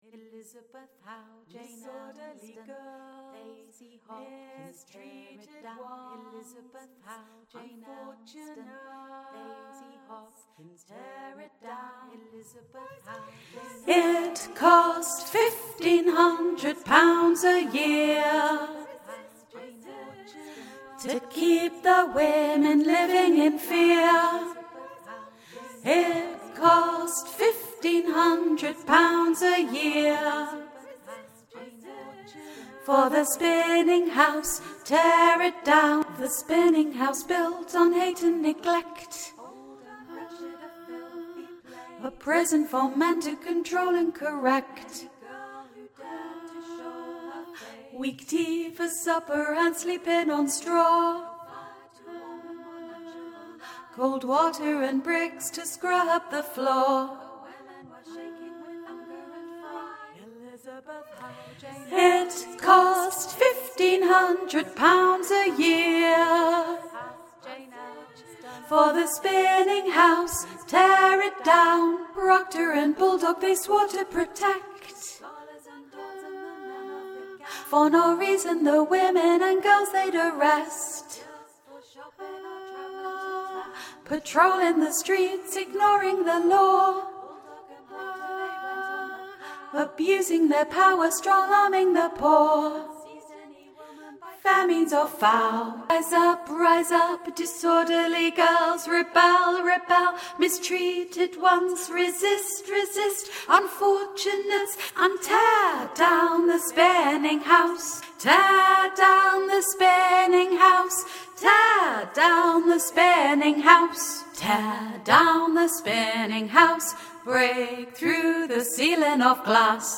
Cycle of Songs: Alto Part for 'Unsung Women'
NOTE: This audio file is a demo version and is for the purpose of helping the choir leader teach their choir the parts of the song.